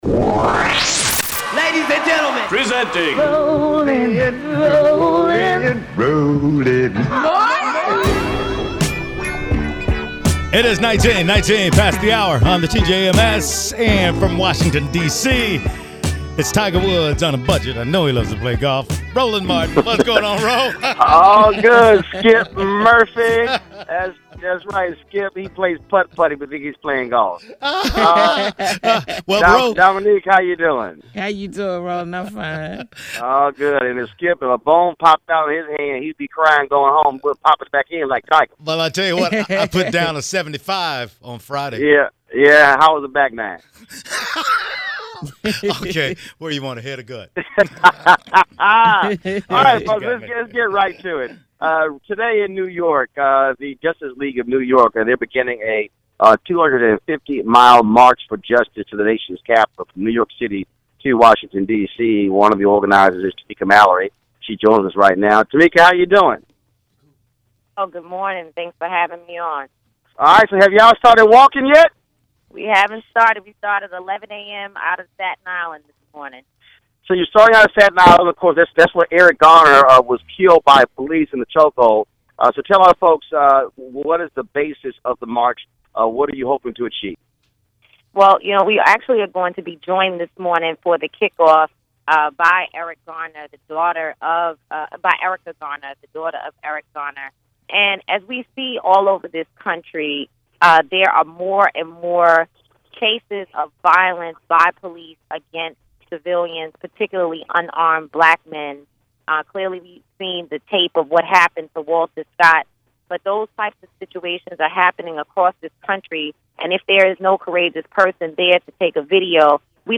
Roland Martin talks to Tamika Mallory about the Justice League NYC‘s march to Washington, D.C. from New York to pass legislature in light of the horrific shooting death of Walter Scott, the South Carolina man killed by police officer Michael Slager.